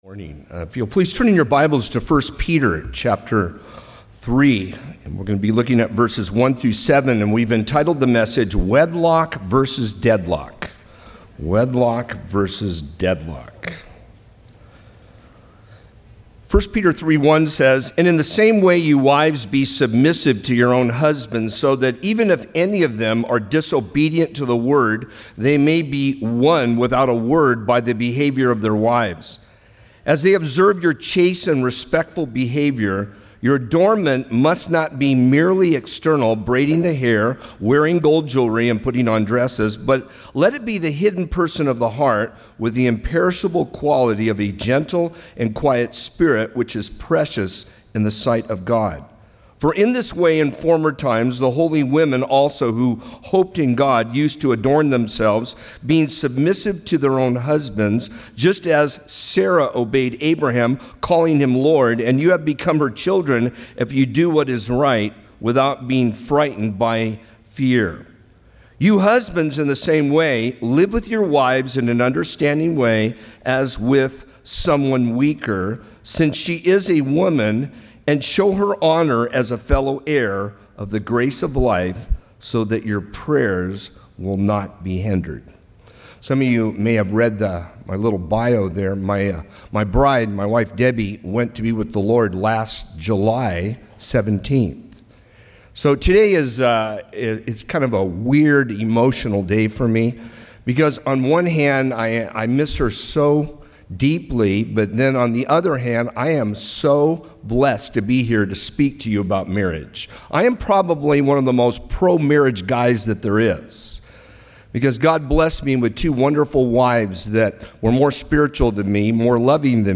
Marriage Conference 2021